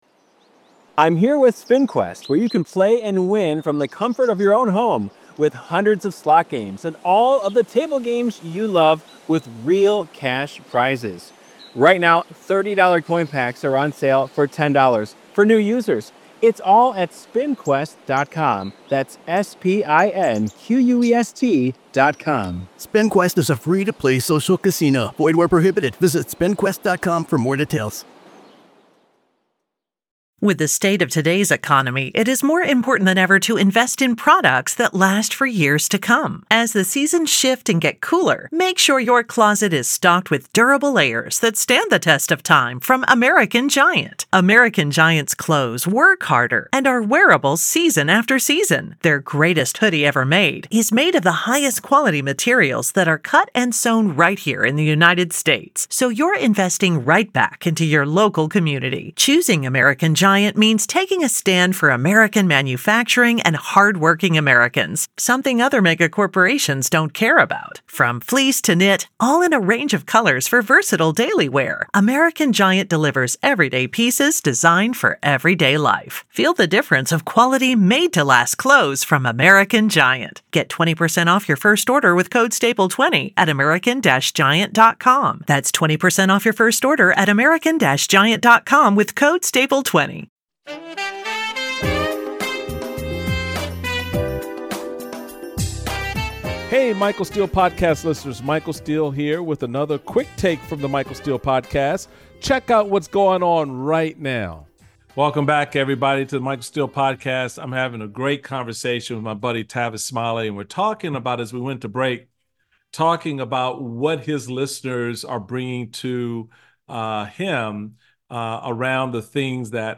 Michael Steele speaks with Tavis Smiley, entrepreneur, broadcaster, best-selling author, and host of Tavis Smiley, airing weekdays from 9am-12pmPT on KBLA Talk 1580.